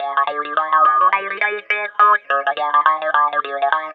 RI GTR 2.wav